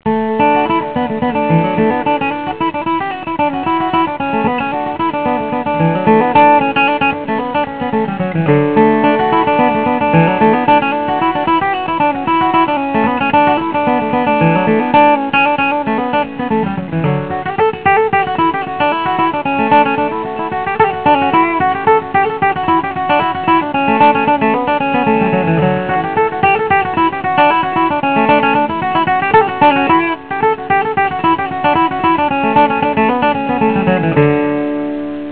I recorded the songs right at my desk, on my Macintosh.
Fiddle and Banjo Tunes: